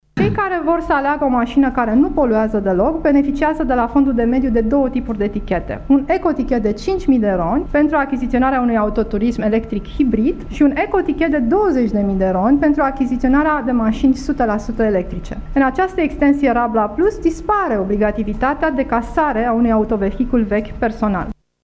Programul „Rabla” are, în acest an, o nouă secţiune intitulată „Rabla Plus” prin care sunt stimulaţi cei care achiziţionează maşini electrice. Ministrul Mediului, Cristiana Paşca Palmer, a explicat care sunt condiţiile în care un român poate beneficia de finanţare pentru a-şi cumpăra un astfel de autoturism: